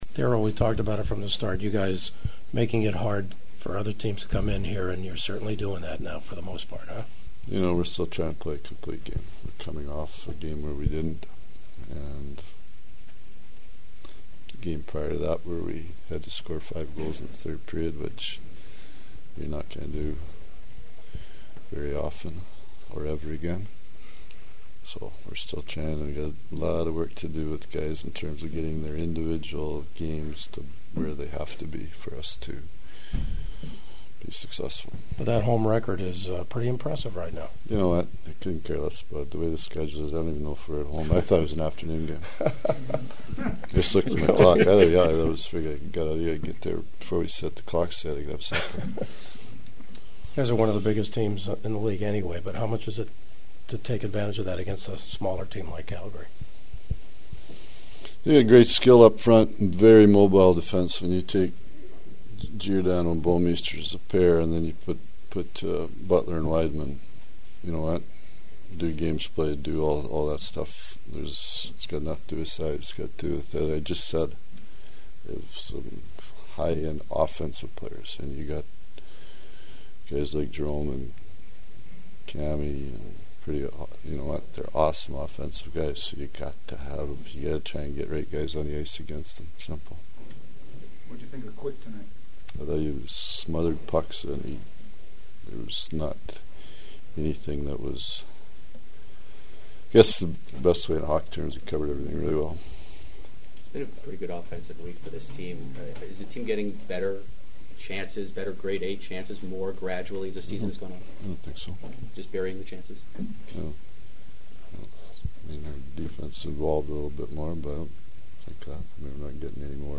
Kings head coach Darryl Sutter who’s first year behind San Jose’s bench was Hrudey’s final year in the NHL: